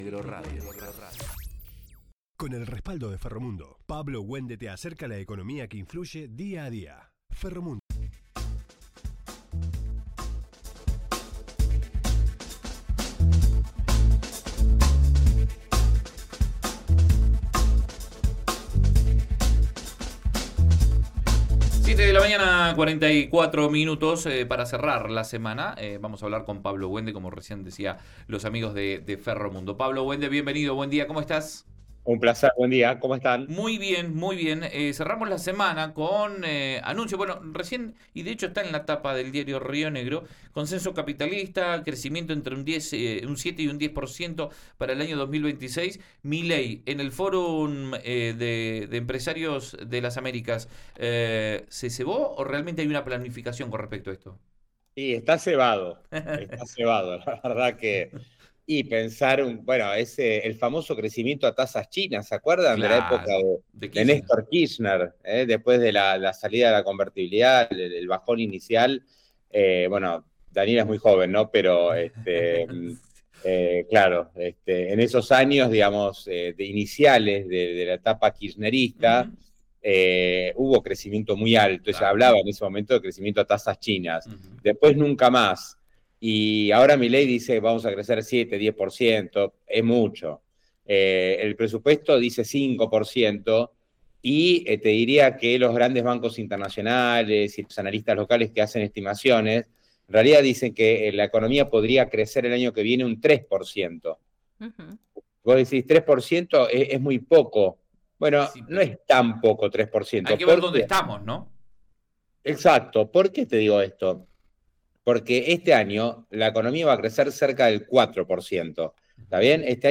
Sin embargo, el analista señaló que el arrastre que deja el 2025 para el 2026 «es cero».